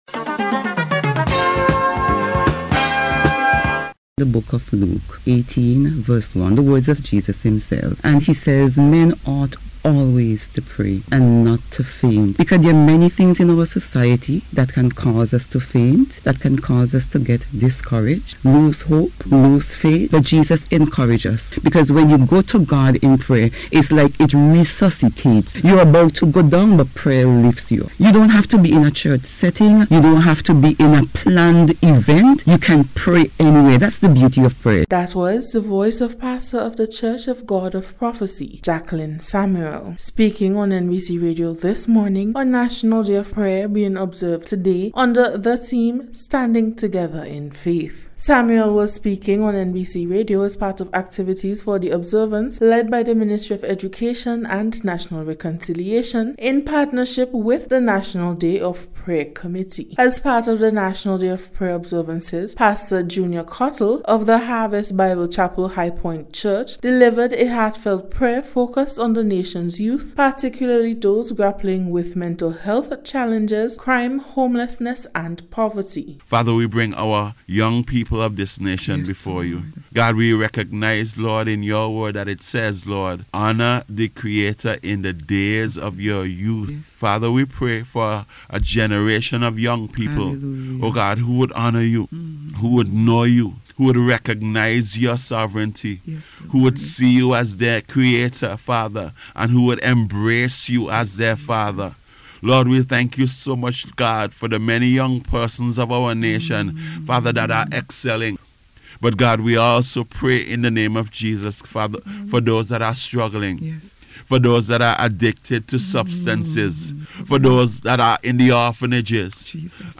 NBC’s Special Report- Monday 6th October,2025